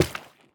minecraft / sounds / dig / coral3.ogg
coral3.ogg